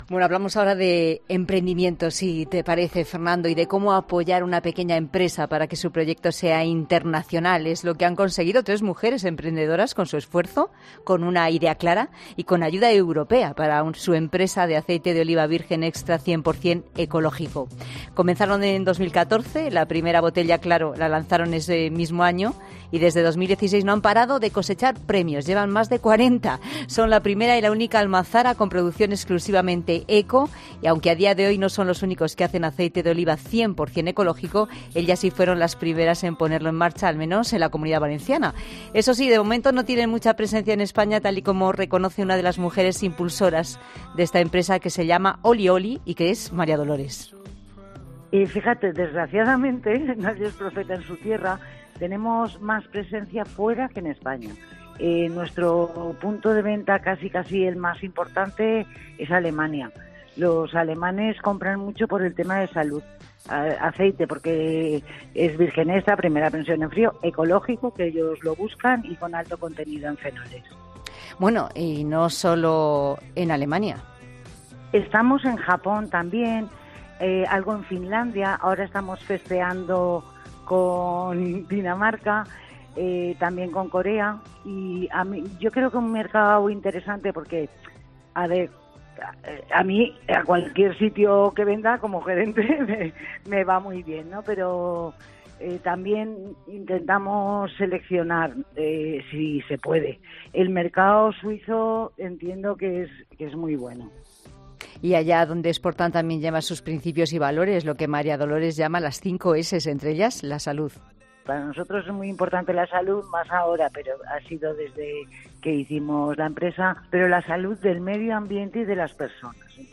Sobre ello hemos hablado en 'La Tarde' con Ernest Urtasun, diplomático, economista y eurodiputado por el grupo de los verdes, que ha explicado que la primera parte se recibirá una vez se apruebe el Plan Nacional español.